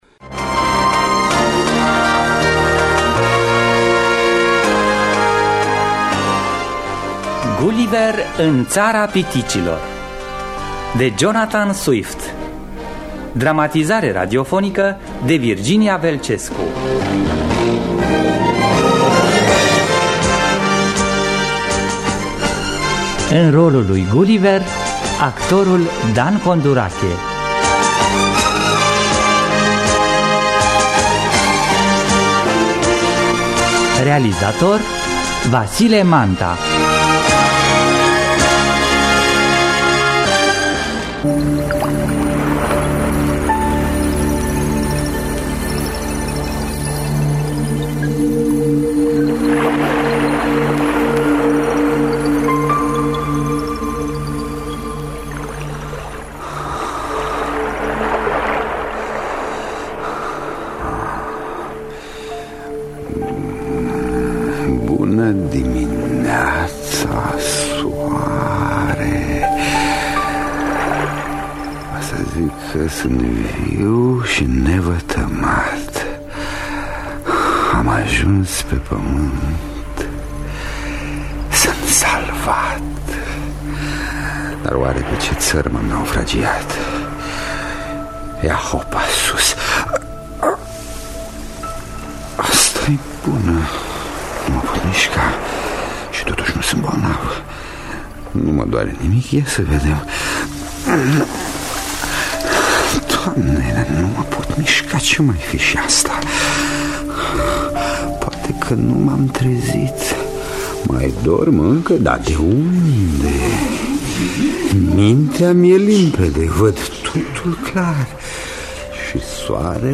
Gulliver în Ţara piticilor de Jonathan Swift – Teatru Radiofonic Online